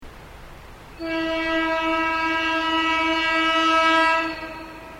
タイホーン（主に電車、気動車の音）
磐越西線 中山宿−上戸間で録音。ホイッスルも同時に鳴っている？